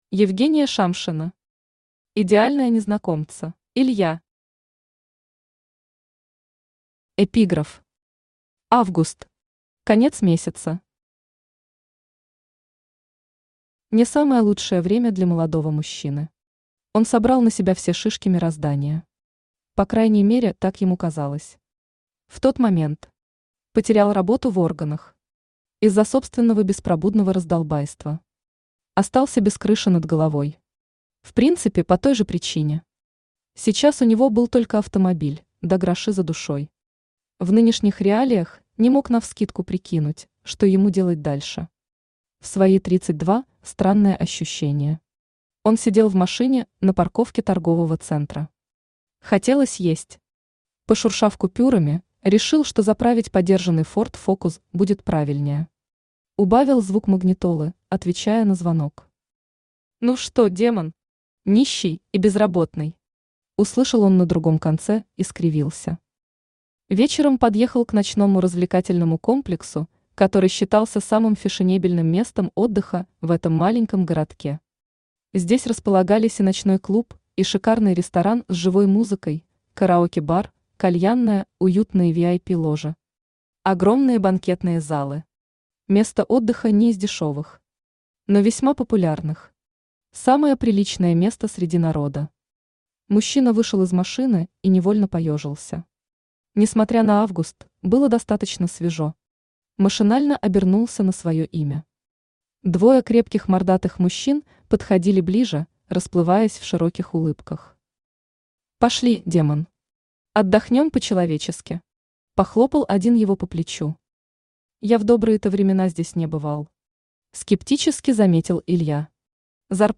Aудиокнига Идеальные Незнакомцы Автор Евгения Шамшина Читает аудиокнигу Авточтец ЛитРес. Прослушать и бесплатно скачать фрагмент аудиокниги